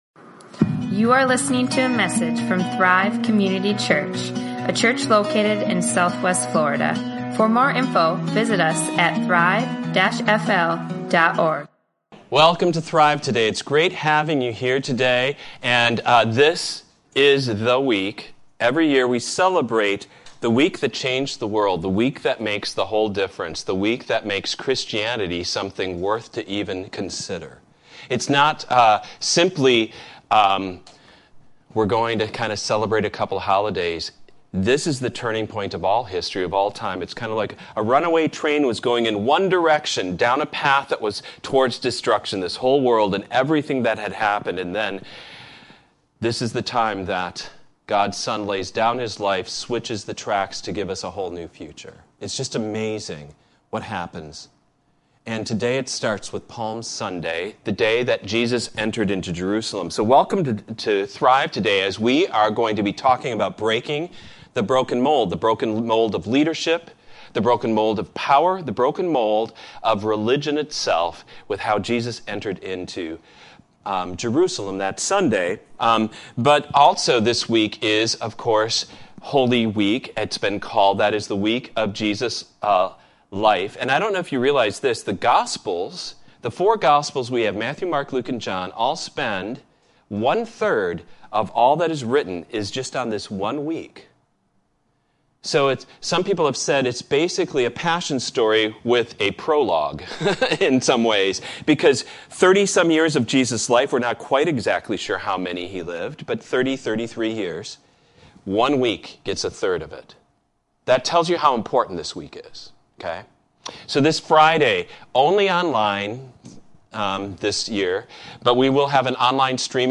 Breaking The Broken Mold | Sermons | Thrive Community Church